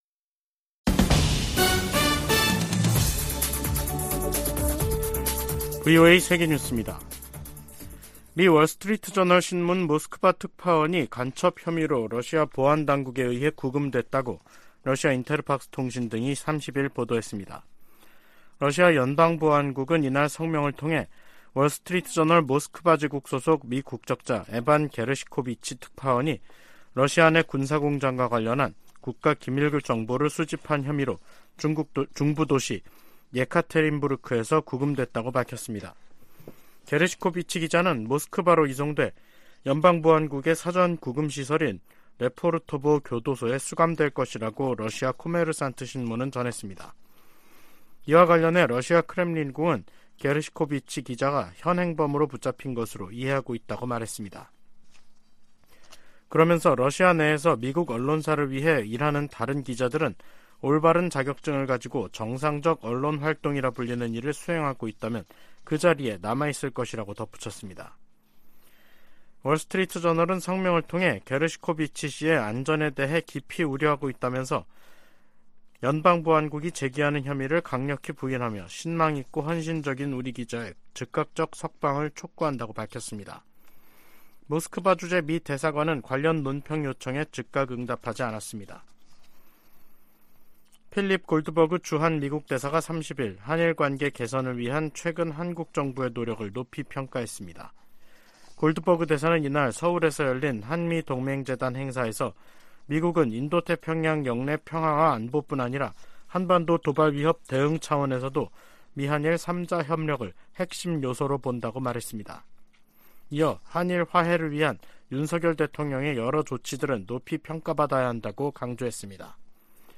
VOA 한국어 간판 뉴스 프로그램 '뉴스 투데이', 2023년 3월 30일 3부 방송입니다. 조 바이든 미국 대통령과 윤석열 한국 대통령은 제2차 민주주의 정상회의에서 공동성명을 내고 양국은 공동의 민주적 가치와 인권 존중을 기반으로 깊은 유대를 공유하고 있다고 밝혔습니다. 미 국무부는 한반도 비핵화 의지를 거듭 확인했습니다. 미국 공화당 중진 상원의원이 한국과의 핵 연습을 확대해야 한다고 주장했습니다.